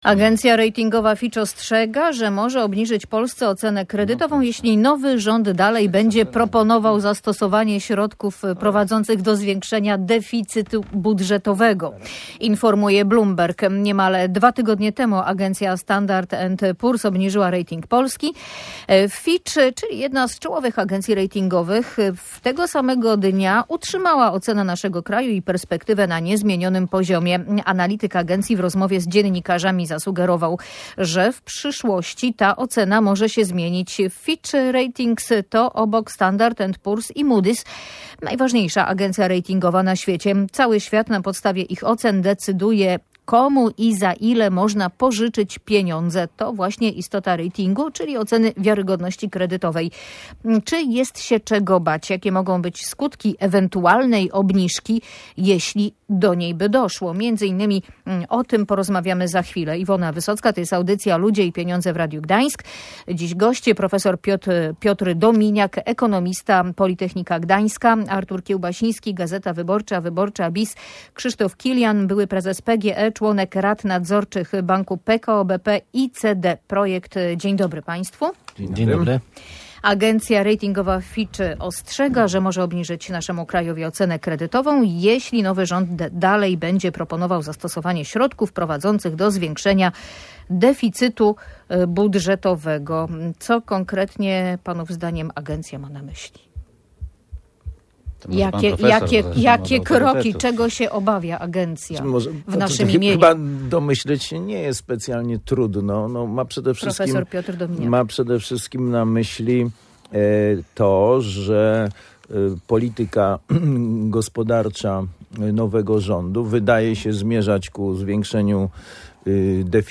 Tematem zajęli się eksperci w audycji Ludzie i Pieniądze.